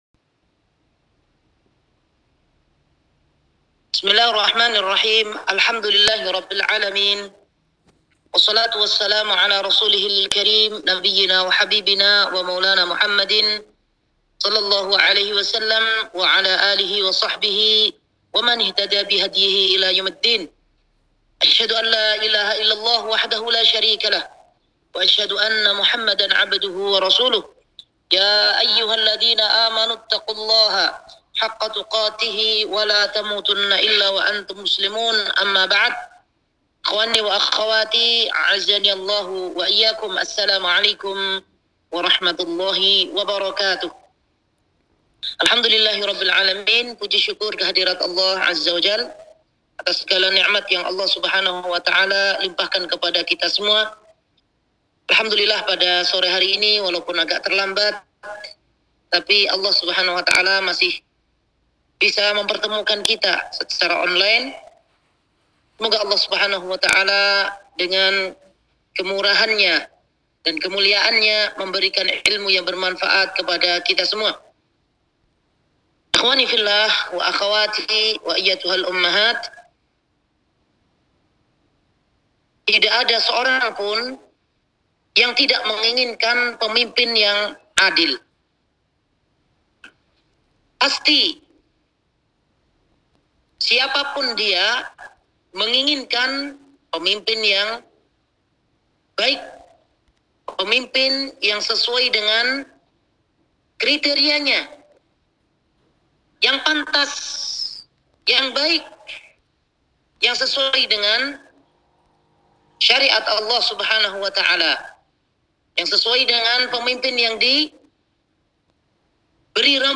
Kajian Online